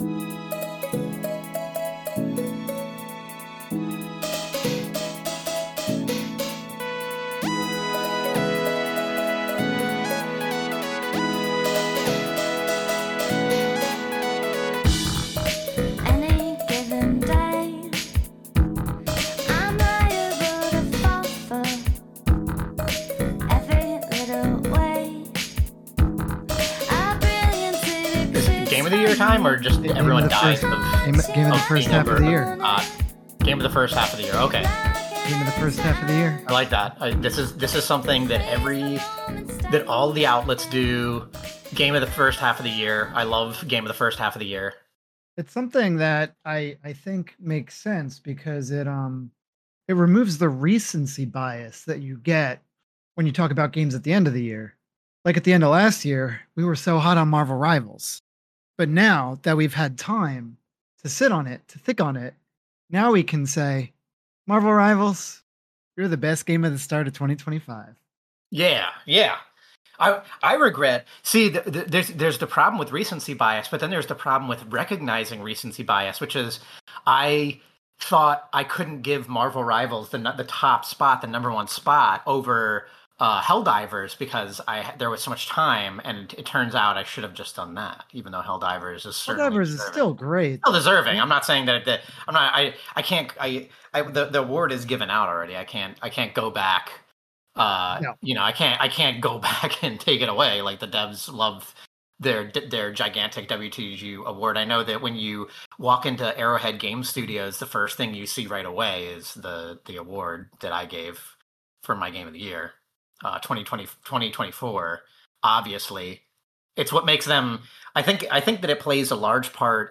WTDG+Podcast+Ep.+339+-+AI+Generated+Episode.mp3